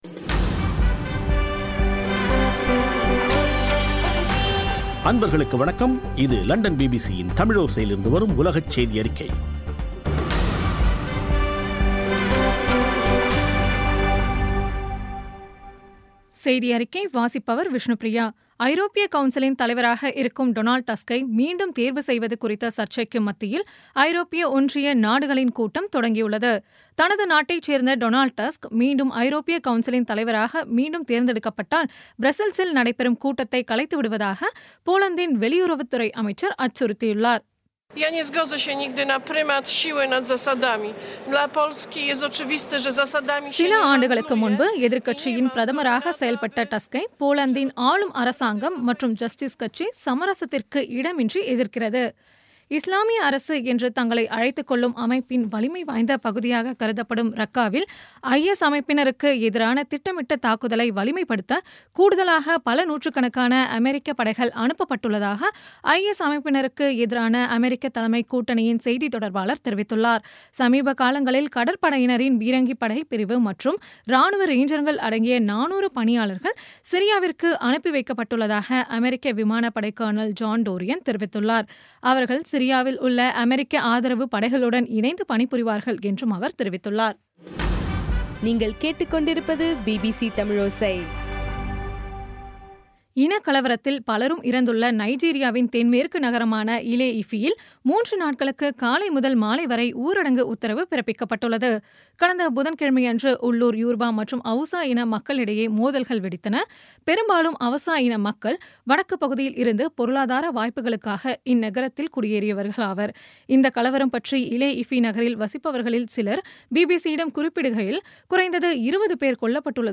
பிபிசி தமிழோசை செய்தியறிக்கை (09/03/17)